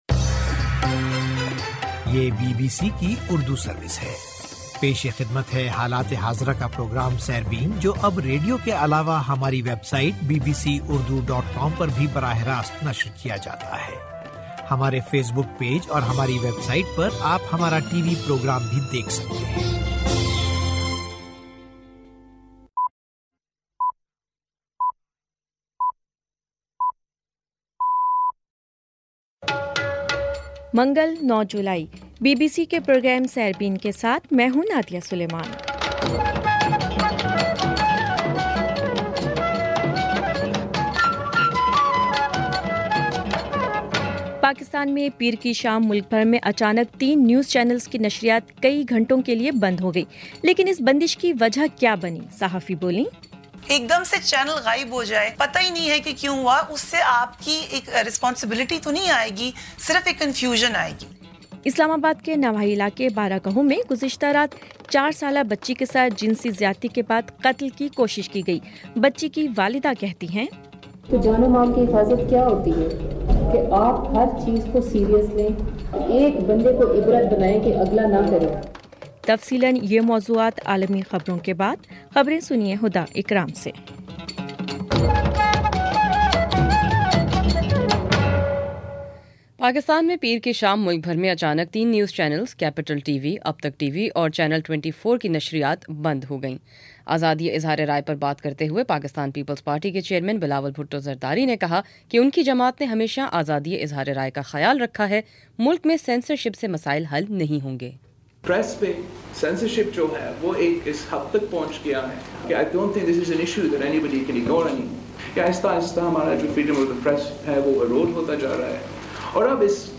منگل09 جولائی کا سیربین ریڈیو پروگرام